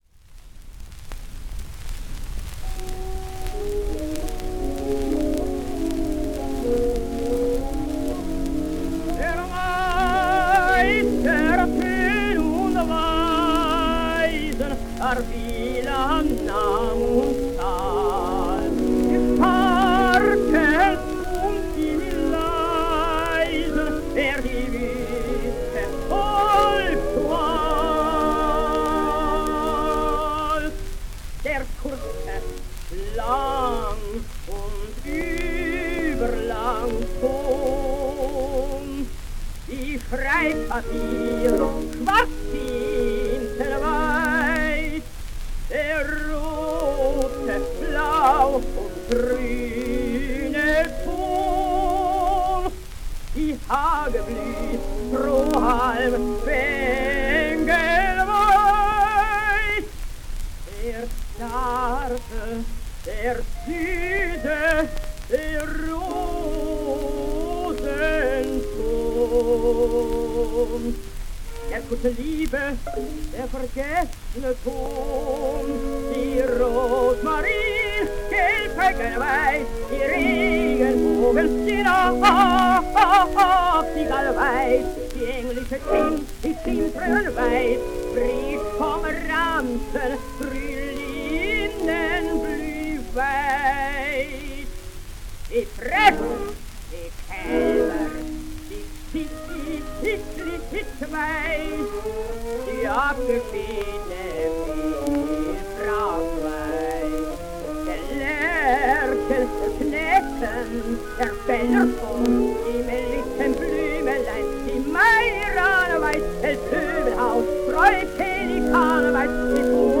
Hans Bechstein singsDie Meistersinger von Nürnberg: